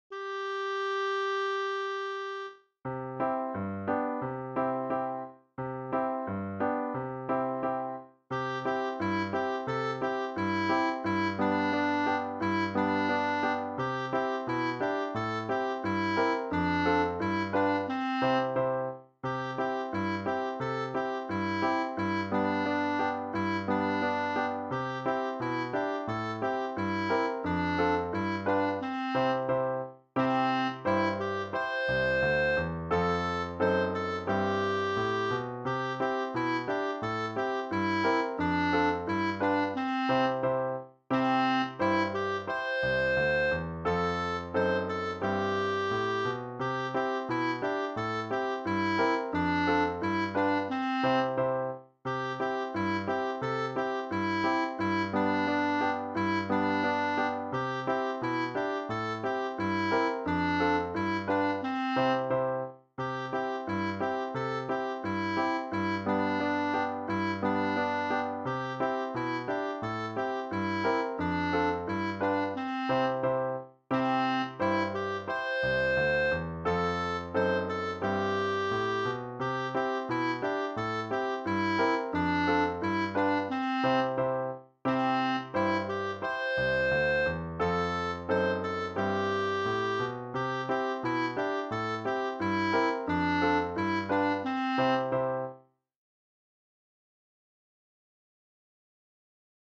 Ici, on pourra acceder à une version accompagnée des mélodies et chansons apprises lors de nos cours.
Une mélodie joyeuse très connue et qui composée sur les notes DO – RE – MI – SOL – LA – DO.